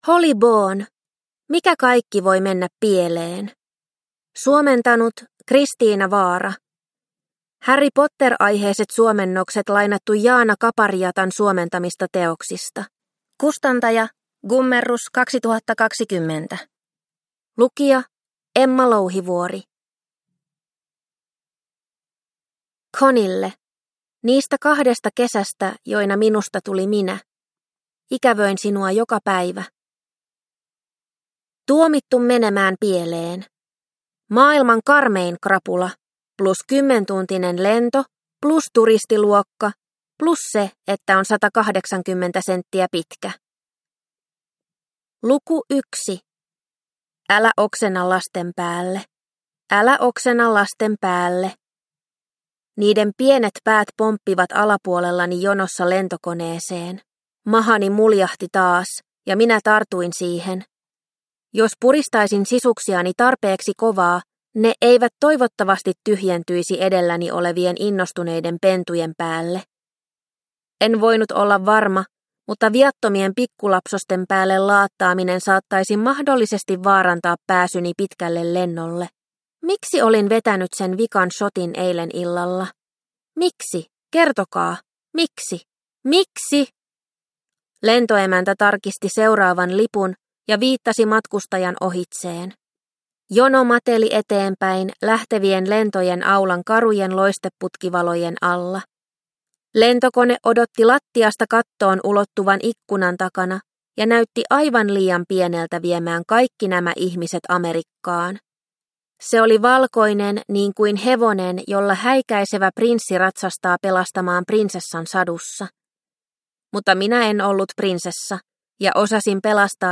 Mikä kaikki voi mennä pieleen? – Ljudbok – Laddas ner